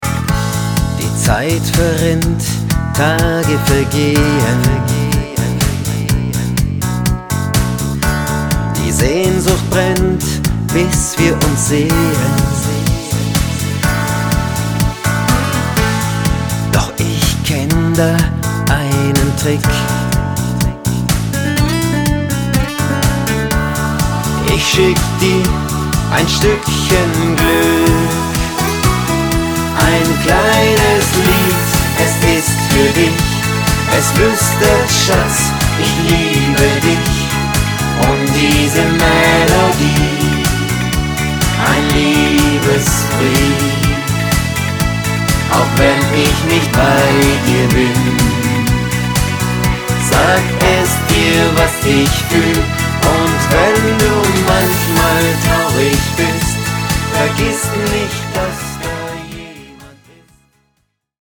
Genre: Schlager